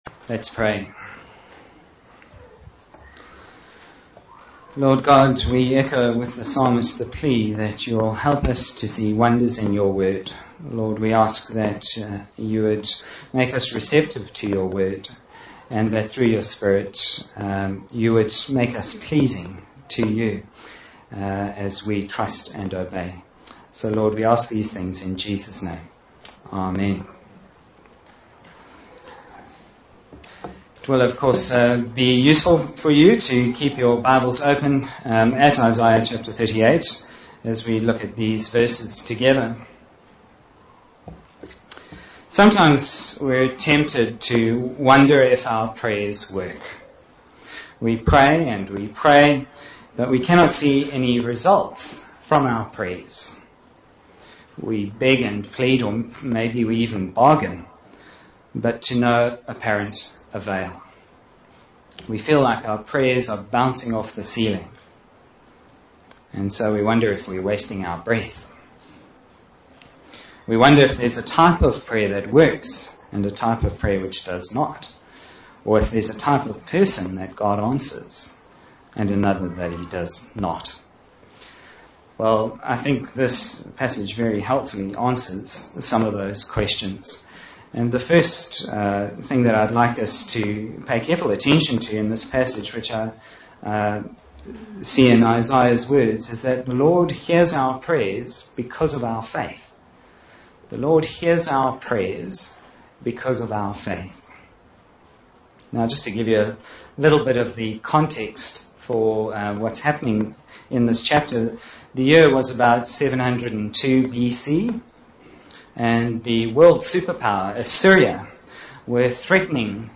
Bible Text: Isaiah 38:1-10 | Preacher